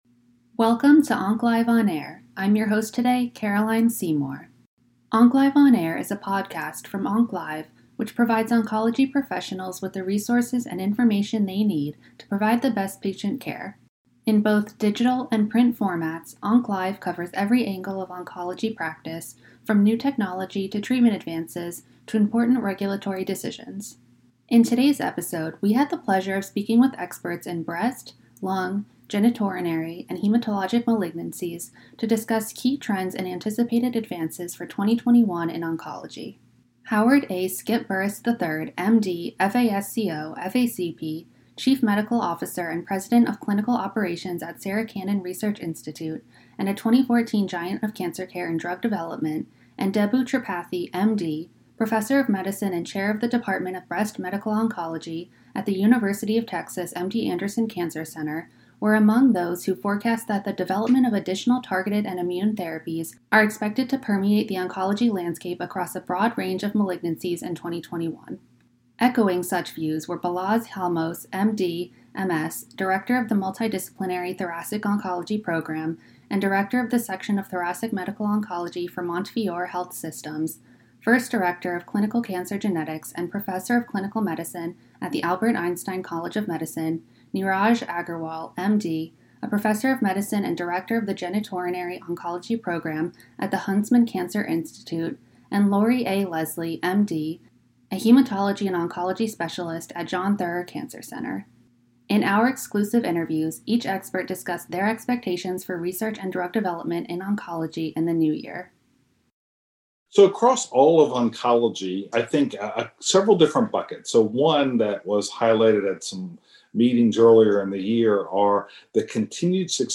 In our exclusive interviews, each expert discussed their expectations for research and drug development in oncology in the new year.